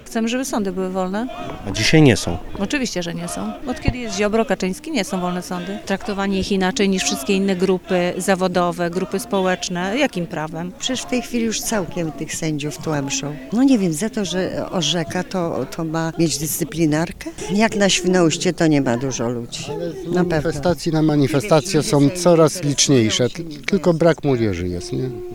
– Od kiedy jest Ziobro i Kaczyński sądy nie są wolne – mówiła jedna z uczestniczek wydarzenia.
– Brak jest młodych ludzi – twierdzi protestujący mężczyzna.
SWIN-SONDA-MANIFESTACJA.mp3